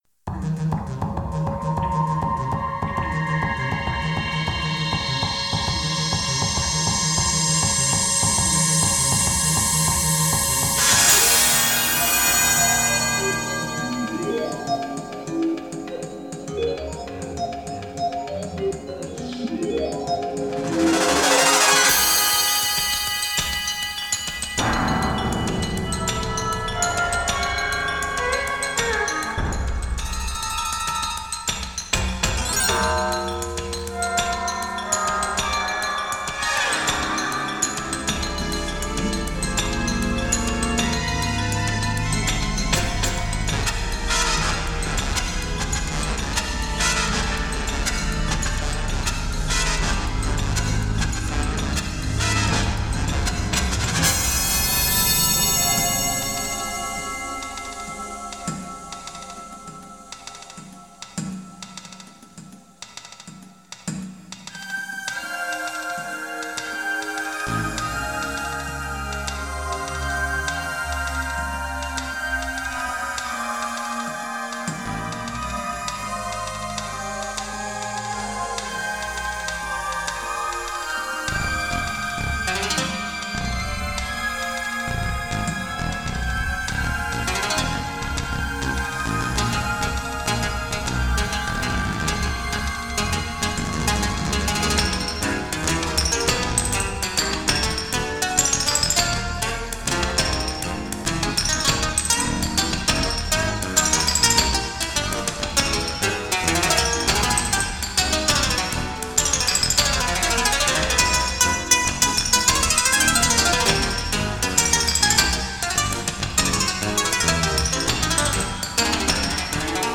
for Electronic music, Video, Laser, Performance and more
なお、残っていた音源がプラネタリウム公演用で、ダイナミクスの幅が広かったものは、若干のコンプ処理をしてあります。
タイトルがイリュージョンで、幻影とか錯覚等と言う言葉を連想し、ゆったりとした動きを連想させますが、速い動きに民族的なゆるやかな響きを乗せた曲になっています。